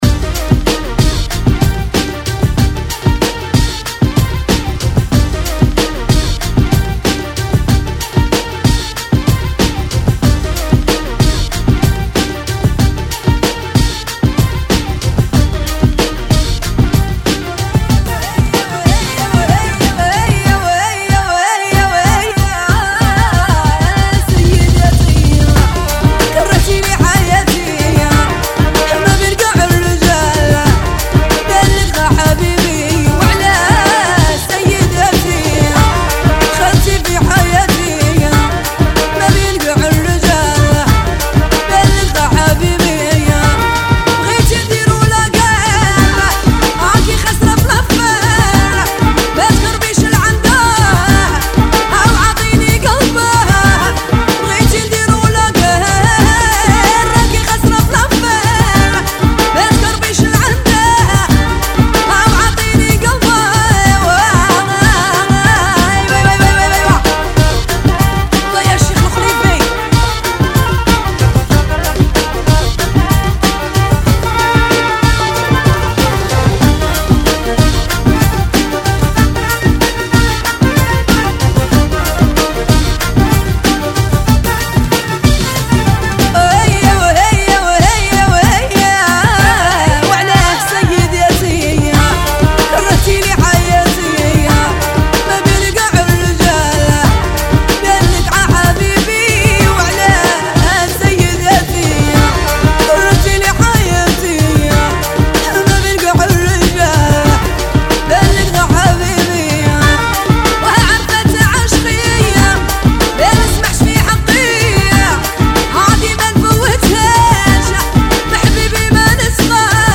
[ 94 Bpm ]
Old School